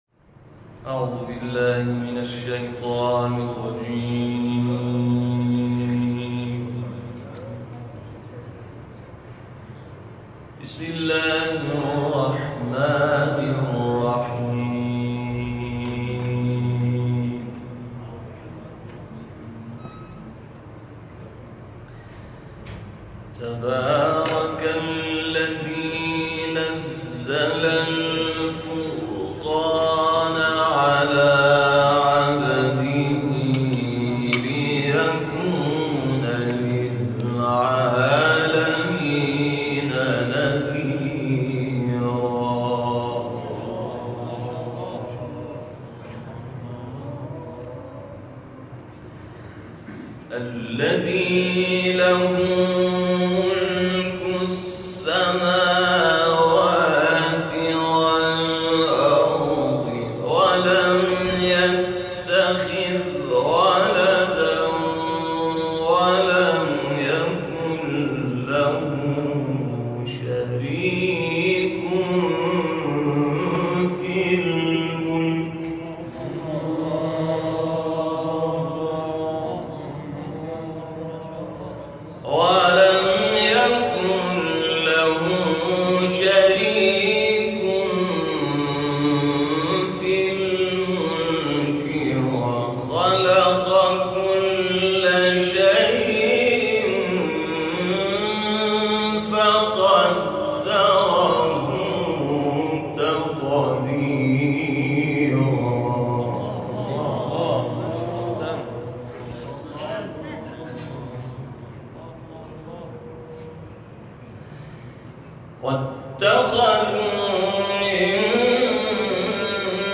تلاوت سوره فرقان